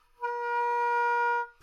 双簧管单音（弹得不好） " 双簧管A4不好的攻击空气
描述：在巴塞罗那Universitat Pompeu Fabra音乐技术集团的goodsounds.org项目的背景下录制。单音乐器声音的Goodsound数据集。
Tag: 好声音 单注 多样本 Asharp4 纽曼-U87 双簧管